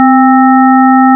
mijnklarinet.wav